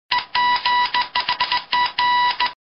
Уведомление о получении сообщения Telegram и другие интересные звуки, которые вам пригодятся.
Звук получения телеграммы
zvuk-polucheniya-telegrammy.mp3